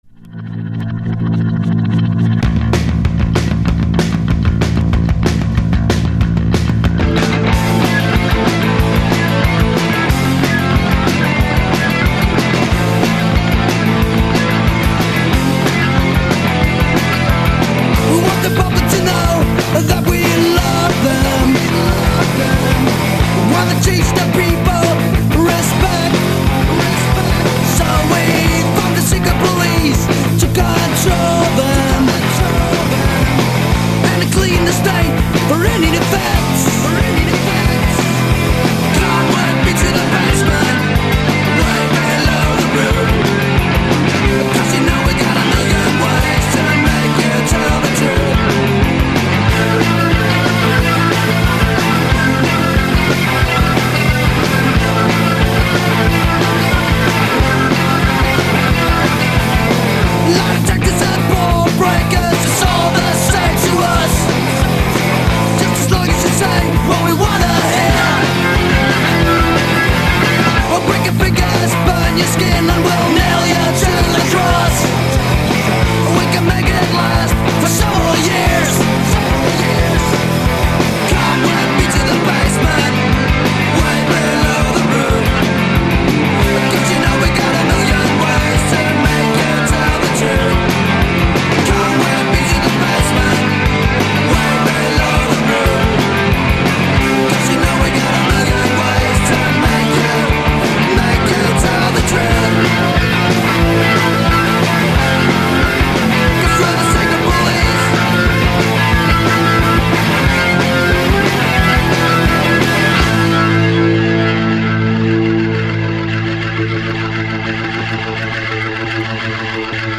Note: this is a vinyl rip so the quality isn't perfect.